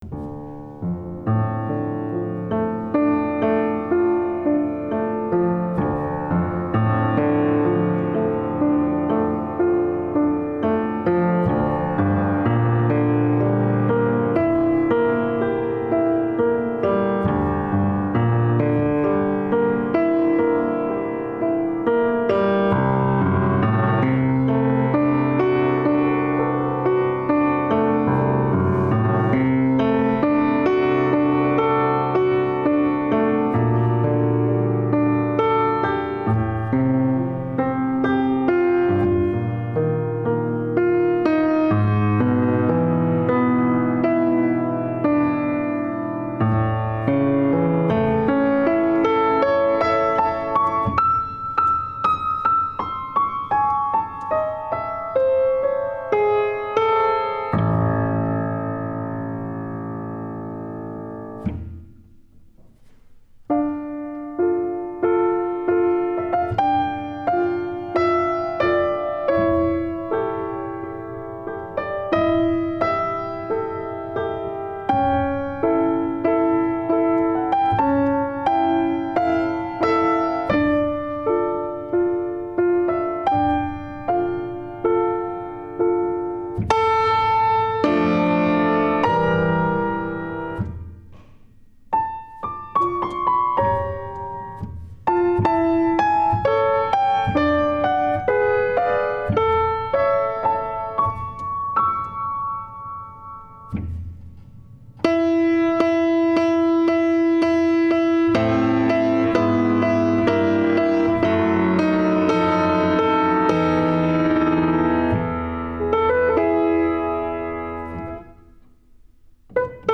Producción de un CD con las obras interpretadas por los estudiantes de recitales, ensamble de jazz, música ecuatoriana y música Latinoamericana de la Universidad de Los Hemisferios del semestre 2017-1 de mayor calidad sonora y mejor ejecución musical